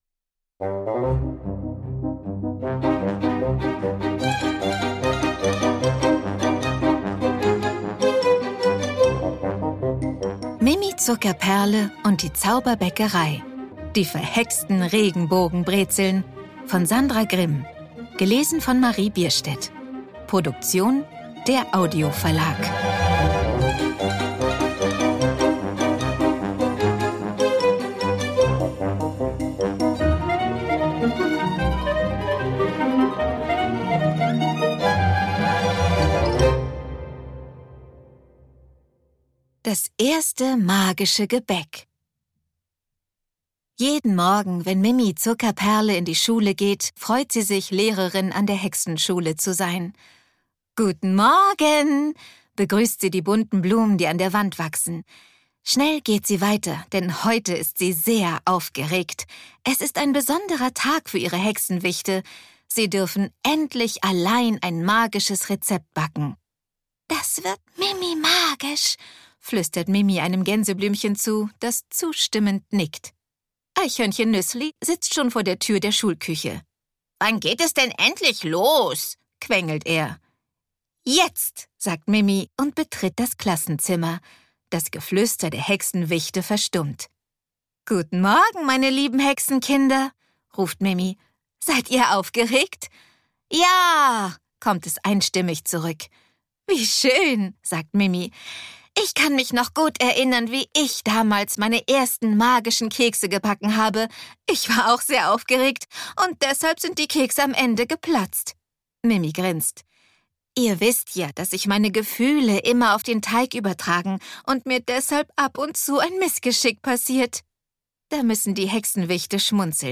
Marie Bierstedt (Sprecher)
Ungekürzte Lesung mit Musik mit Marie Bierstedt